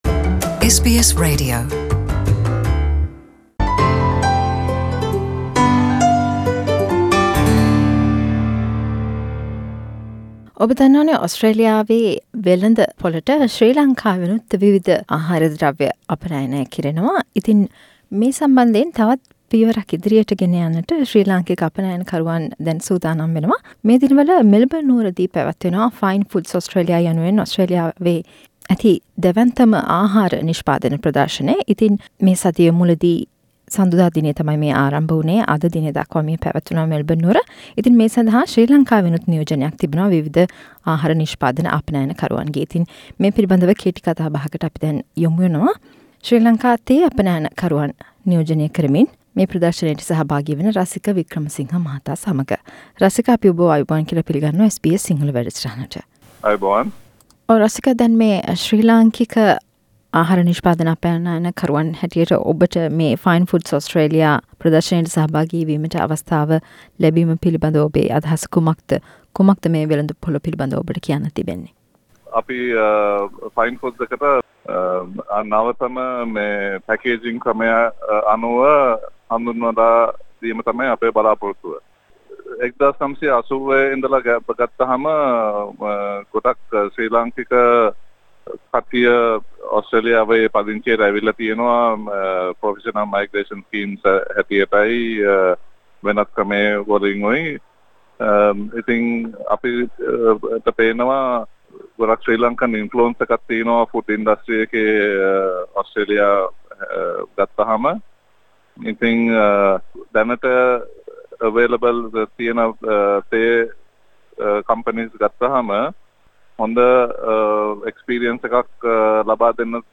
මෙල්බන් නුවර පැවැත්වෙන Fine foods Australia ප්‍රදර්ශනයට සහභාගී වන ශ්‍රී ලාංකික අපනයනකරුවෙකු සමග කතා බහක්